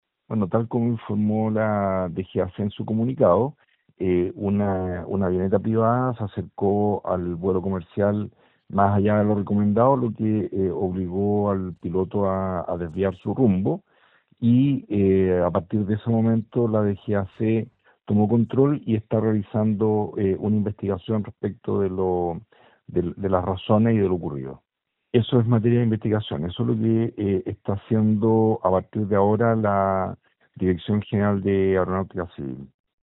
Producto de ello, el avión fue desviado al aeropuerto El Tepual de Puerto Montt, donde aterrizó sin mayores complicaciones, tal como lo confirmó el delegado presidencial de Chiloé, Marcelo Malagueño.
delegado-de-chiloe-.mp3